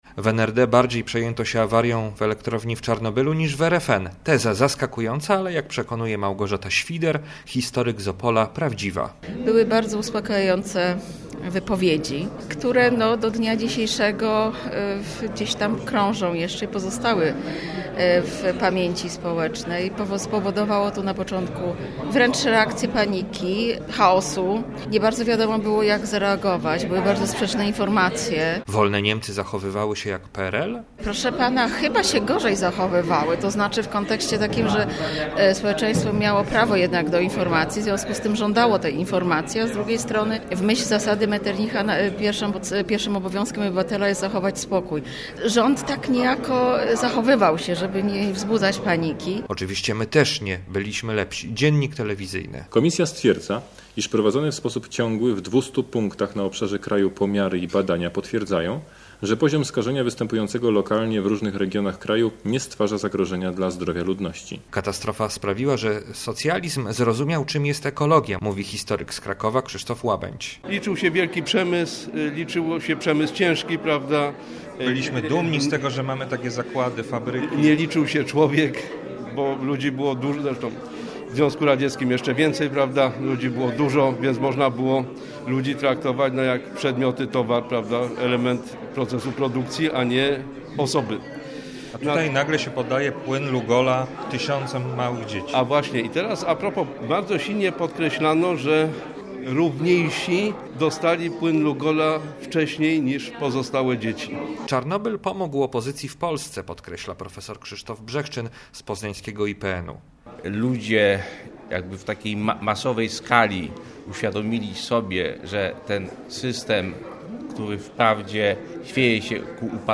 W Poznaniu historycy z całej Polski rozmawiają o ekologicznych skutkach funkcjonowania gospodarki komunistycznej.
7j9w5i8jj5etdui_konferencja-czarnobyl.mp3